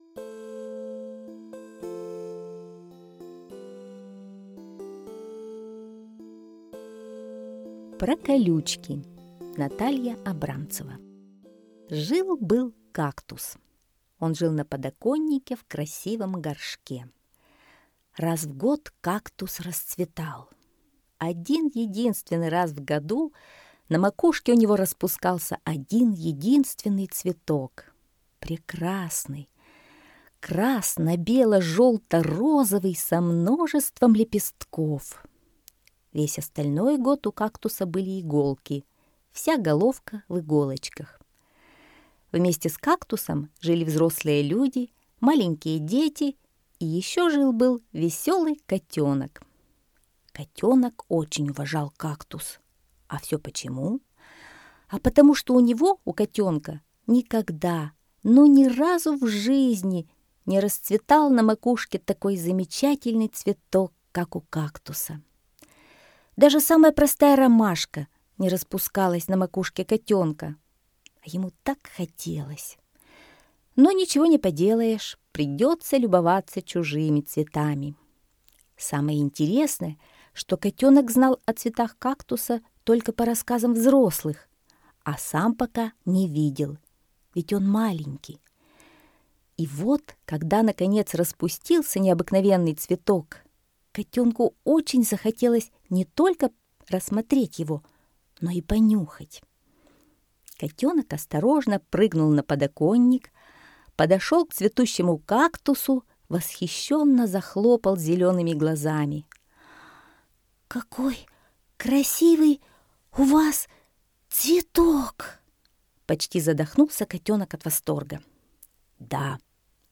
Про колючки - аудиосказка Абрамцевой Н. Сказка про маленького котенка и кактус, который цвел один раз в году.